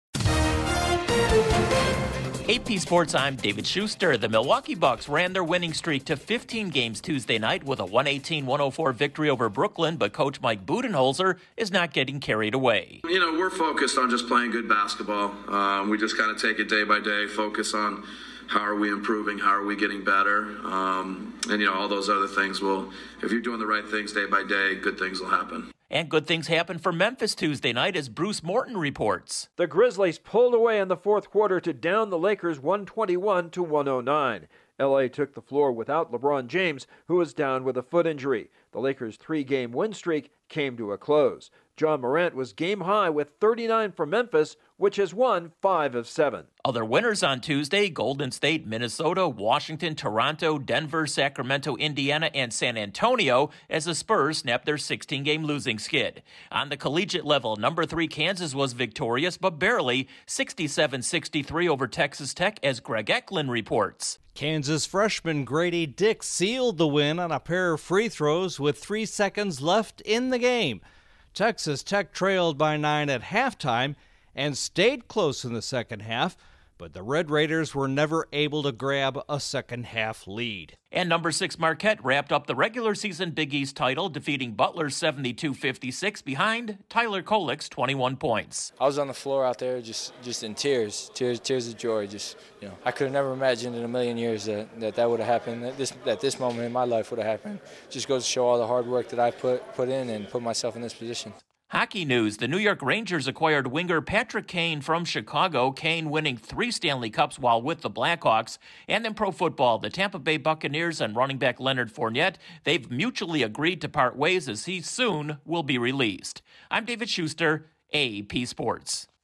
One NBA streak continued and another came to an end, collegiate basketball saw two teams in the top ten win Tuesday night, there's been a huge trade in the NHL and a veteran running back is getting his wish to be released. Correspondent